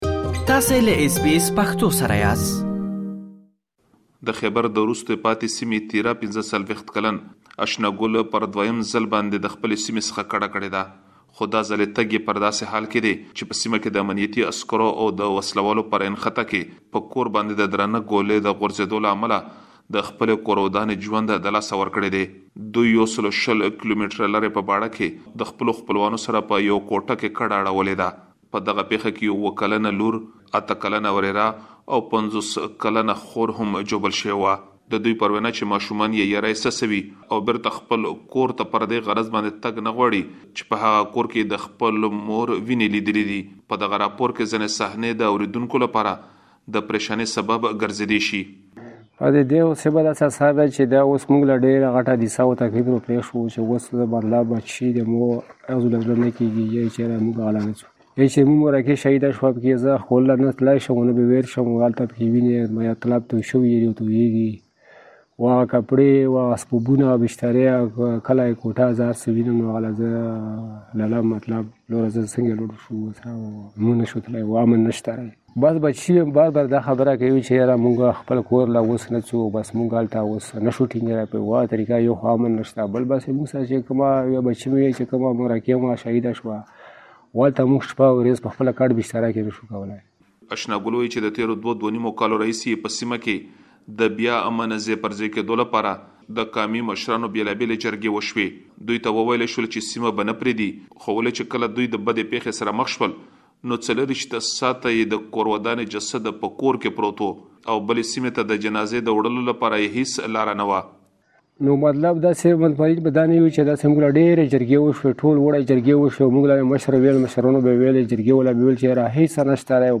د خيبر له لرې پرتو سيمو، په ځانګړي ډول له تېراه څخه خلک يو ځل بيا کډه کولو ته اړ شوي دي. په قبایلي سیمو کې د ناامنیو له زياتېدو سره ولس ته مرګ‌ژوبله اوښتې او جرګو هم تر اوسه نتيجه نه ده ورکړې. د پاکستان امنیتي ځواکونو په خلکو فشار اچولی ترڅو خپلې سیمې پرېږدي او په اپریل میاشت کې بېرته خپلو سیمو ته را ستانه شي. مهرباني وکړئ په دې اړه لا ډېر معلومات په رپوټ کې واورئ.